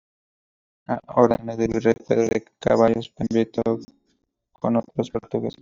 Read more viceroy Frequency 30k Hyphenated as vi‧rrey Pronounced as (IPA) /biˈrei/ Etymology Cognate with French vice-roi In summary From rey (“king”); compare French vice-roi.